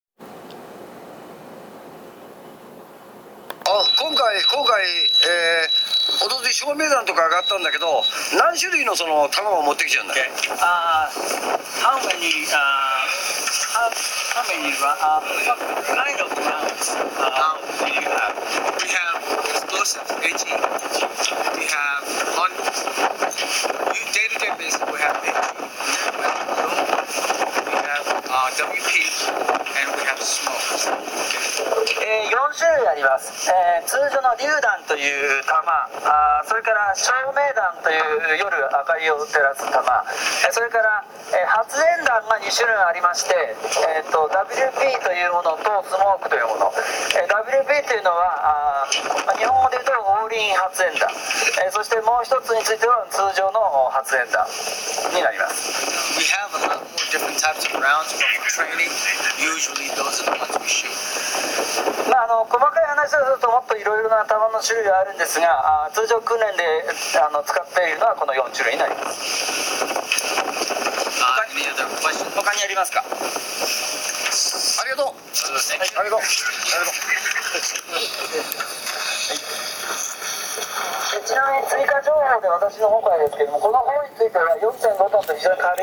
公開訓練で米軍の担当者が説明した音声